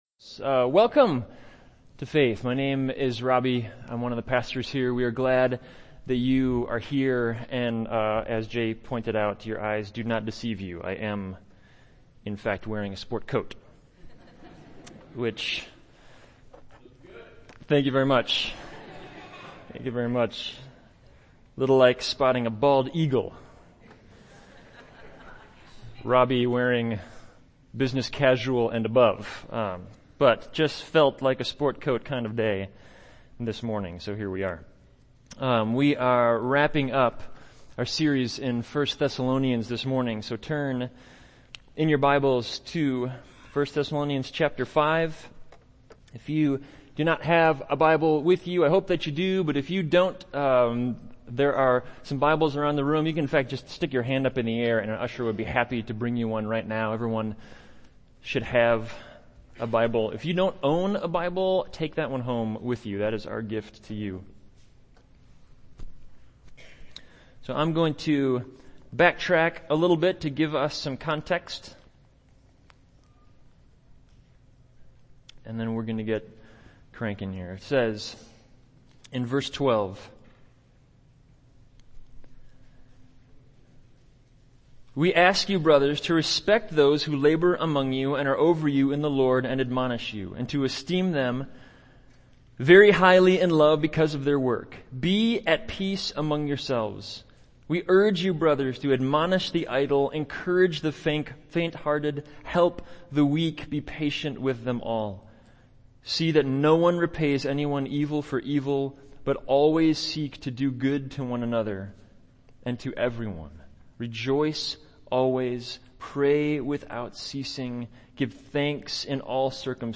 He Will Surely Do It – Faith Church Sermon Audio Archive